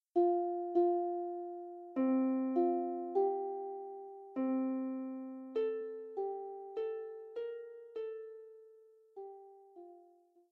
lever or pedal harp